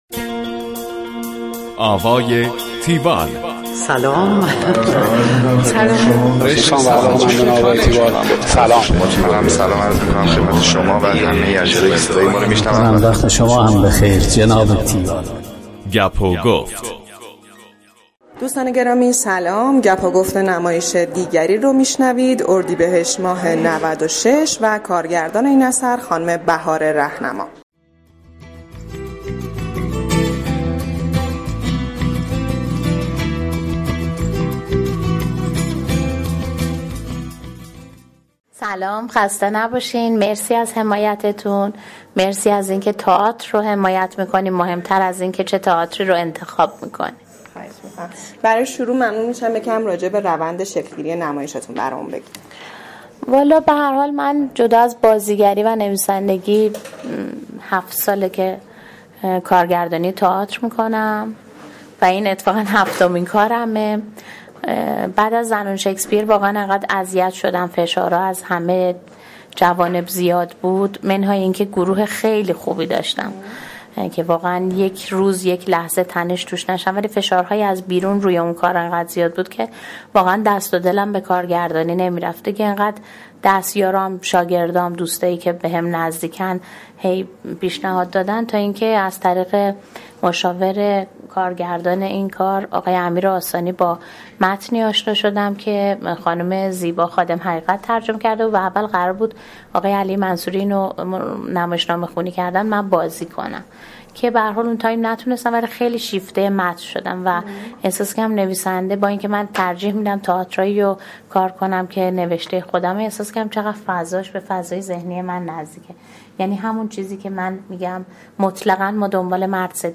گفتگوی تیوال با بهاره رهنما